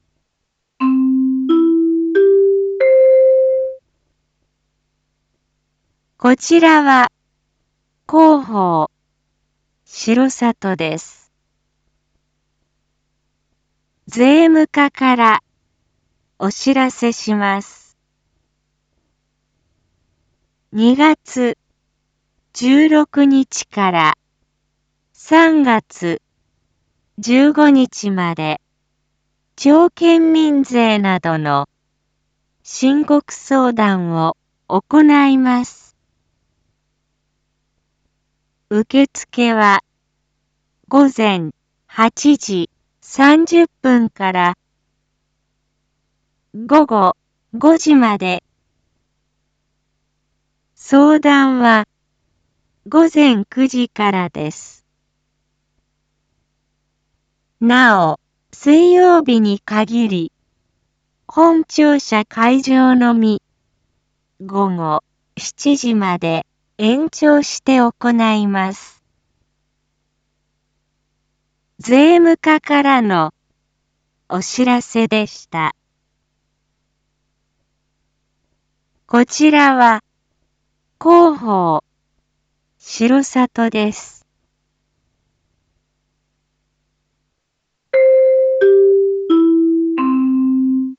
Back Home 一般放送情報 音声放送 再生 一般放送情報 登録日時：2022-03-07 07:01:32 タイトル：申告相談のご案内 インフォメーション：こちらは広報しろさとです。